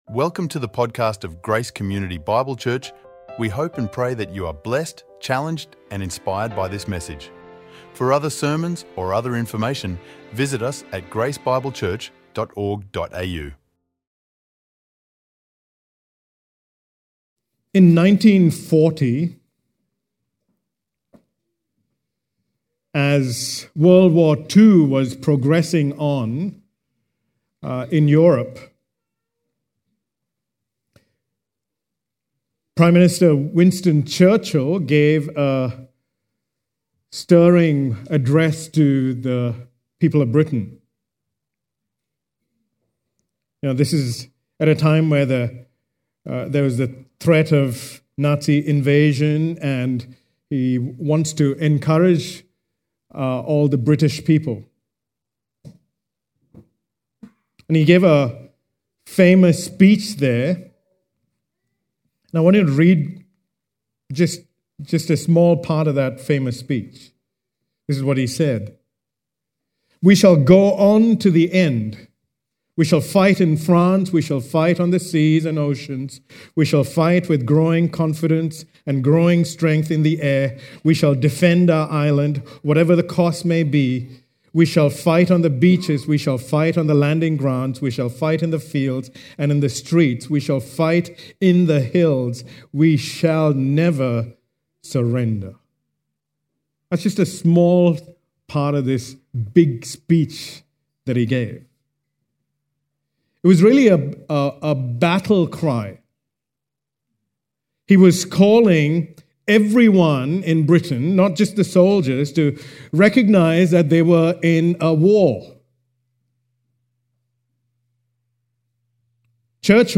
recorded live at Grace Community Bible Church